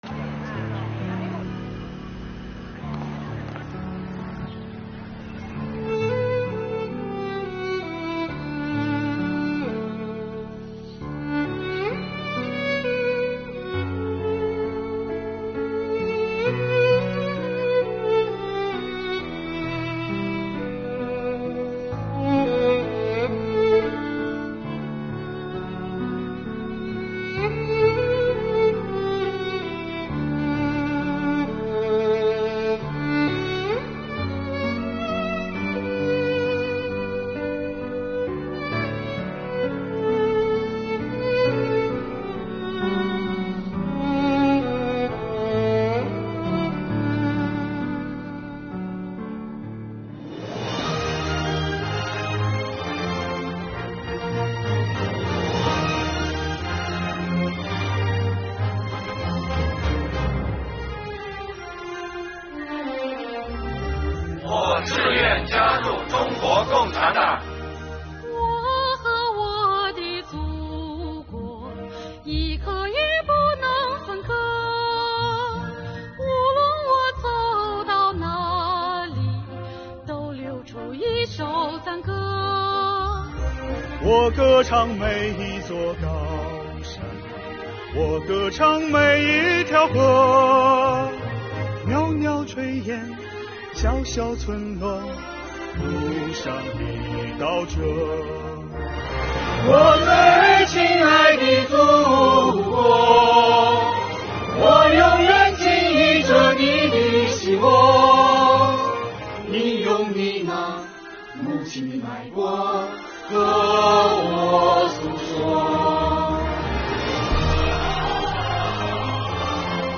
“我和我的祖国，一刻也不能分割……”苍翠松柏间，伴随着悠扬的小提琴声，国家税务总局云南省税务局党委第三巡察组、巡察专项检查组临时党支部与红河哈尼族彝族自治州税务局的党员们，与当地群众共同唱响《我和我的祖国》。